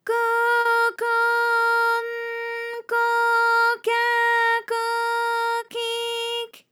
ALYS-DB-001-JPN - First Japanese UTAU vocal library of ALYS.
ko_ko_n_ko_ka_ko_ki_k.wav